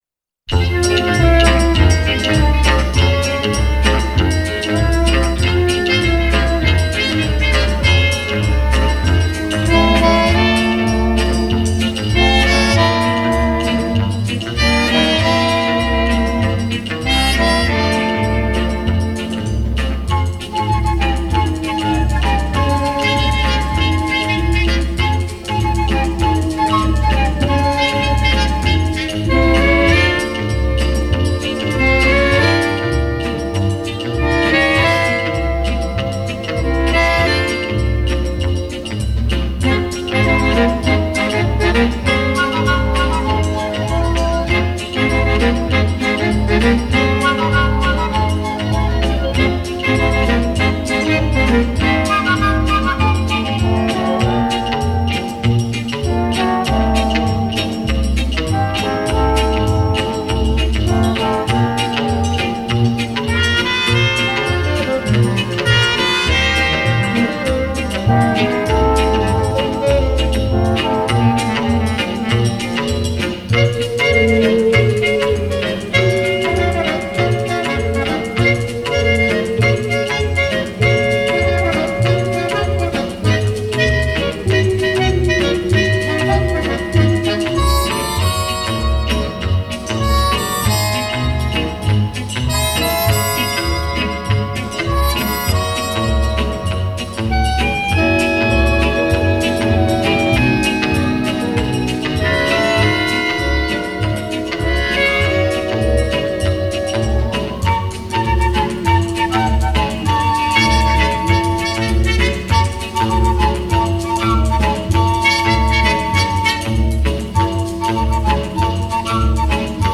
Genre: Pop, Jazz, Soundtrack, Instrumental, Divers, Italian